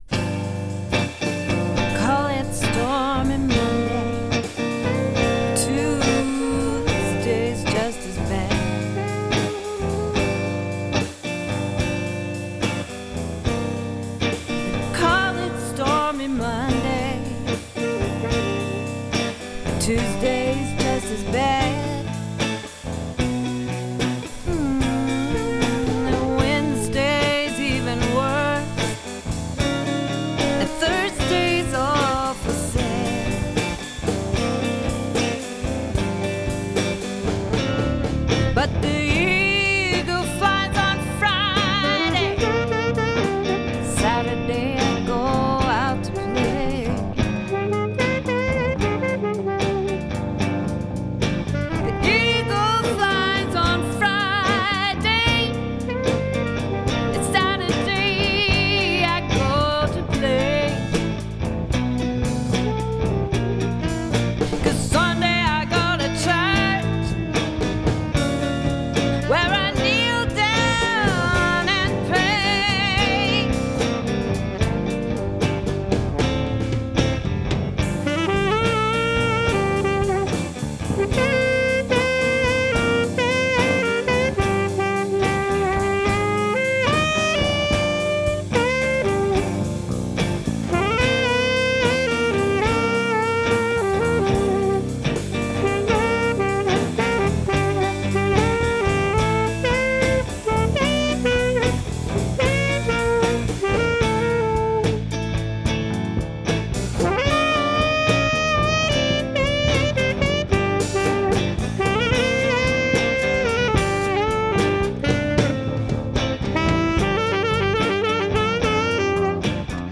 Our studio recording at Glendale and Alta Loma Studios